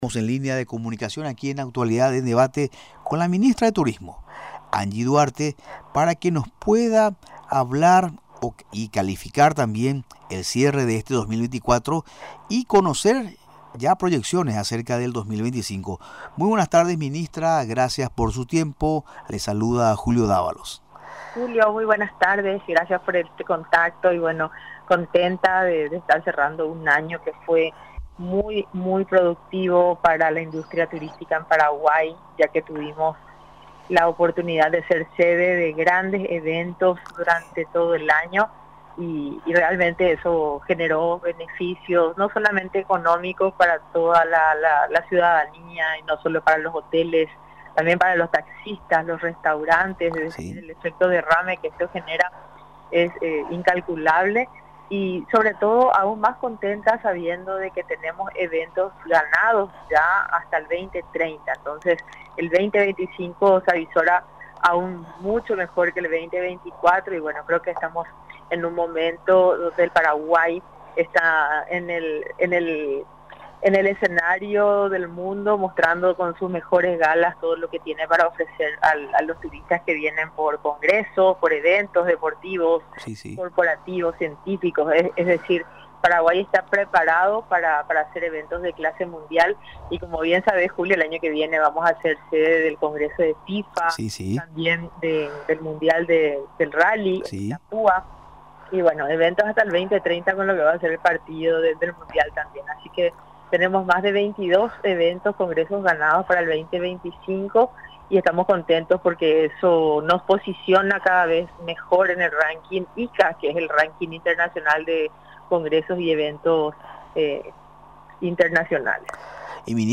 Durante la entrevista en Radio Nacional del Paraguay, calificó al 2024 como auspicioso en todo lo relacionado al turismo.